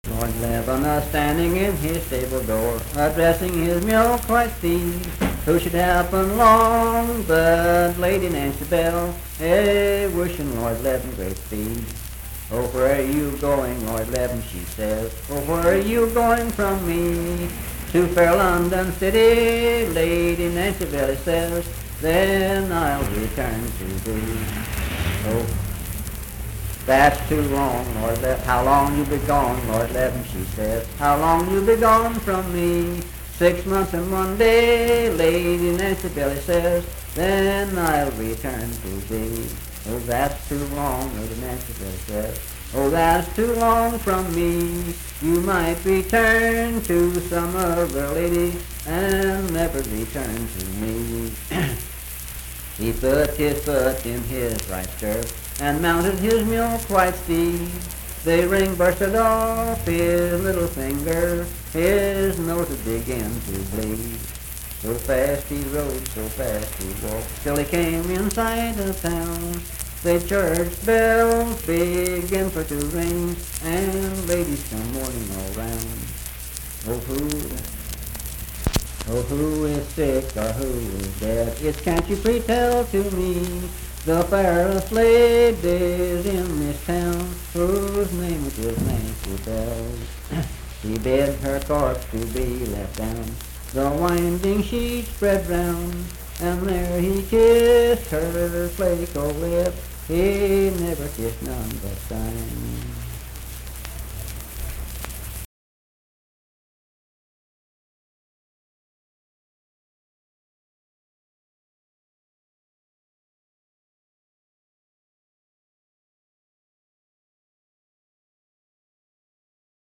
Unaccompanied vocal music performance
Verse-refrain 8(4).
Voice (sung)